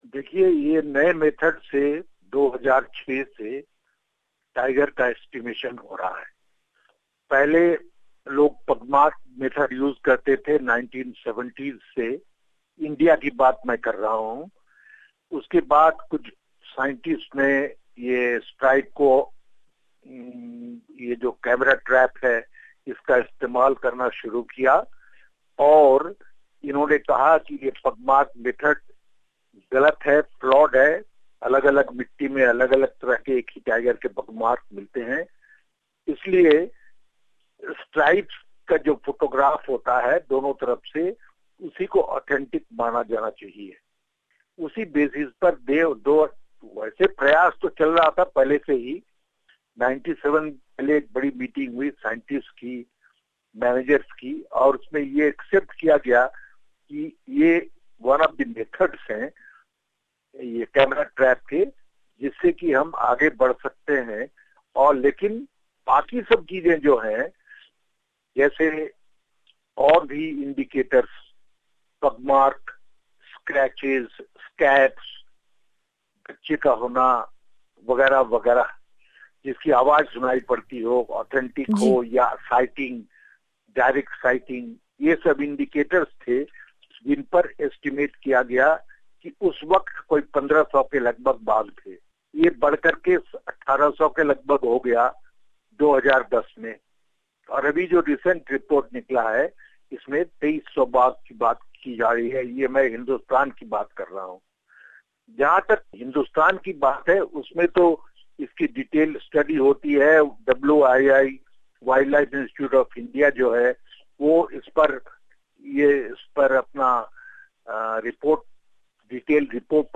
We spoke to well know Tiger Conservationist and Padmashri Awardee, P.K. Sen, on this issue who was Former Director of 'Project Tiger' and Headed WWF's India's Tiger Conservation Programme.